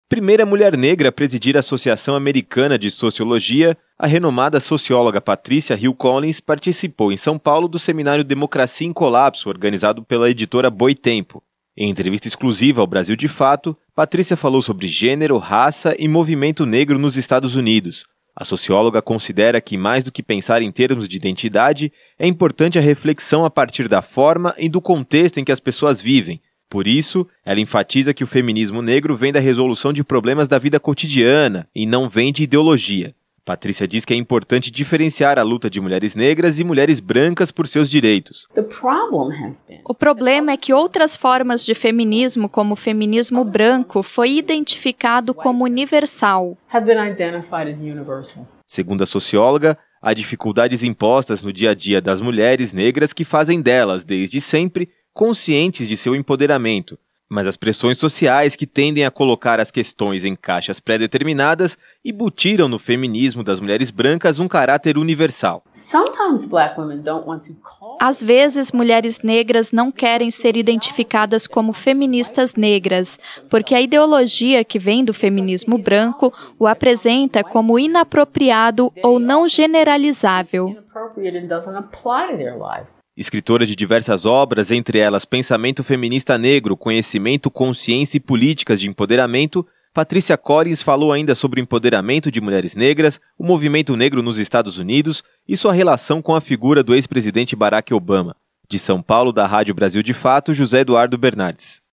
Renomada socióloga estadunidense concedeu entrevista durante seminário "Democracia em Colapso", organizado pela editora Boitempo